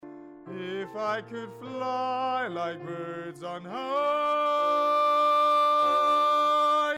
Clipping Rode NT1-A and Phonic Firefly 302
Somehow my recordings start clipping though, when I sing a big louder.